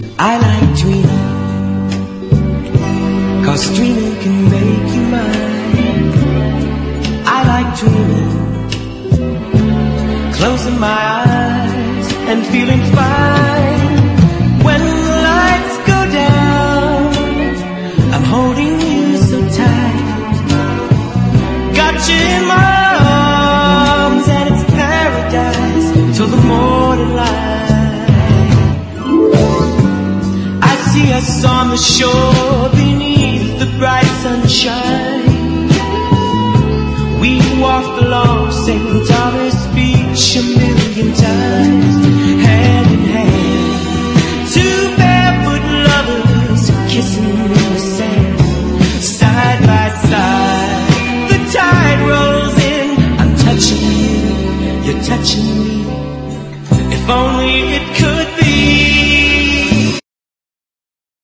COUNTRY ROCK (US)
みずみずしいカントリー・ロック・デュオ！